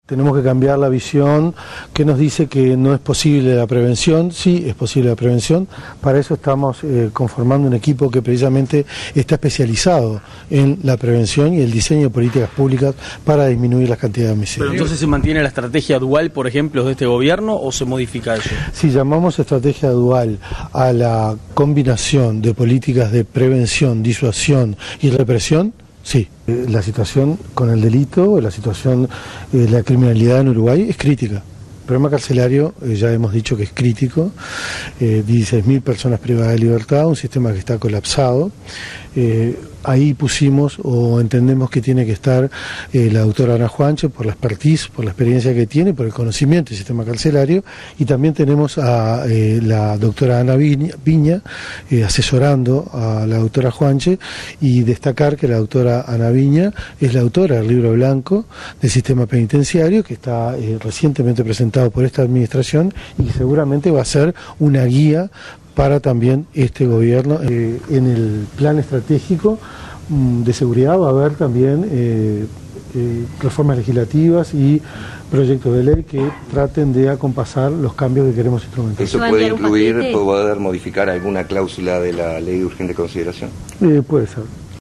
Declaraciones de Carlos Negro